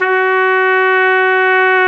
Index of /90_sSampleCDs/Roland L-CDX-03 Disk 2/BRS_Piccolo Tpt/BRS_Picc.Tp 1